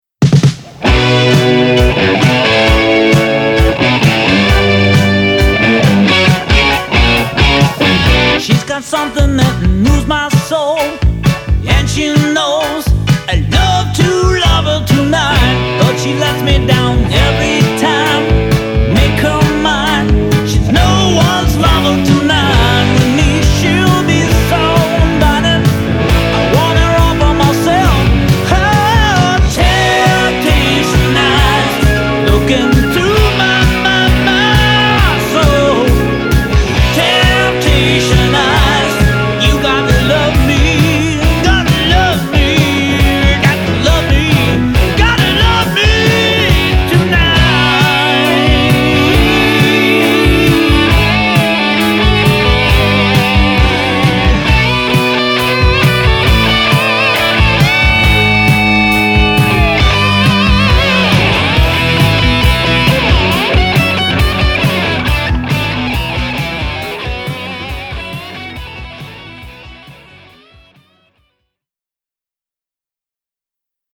Vocals, Guitars, Bass by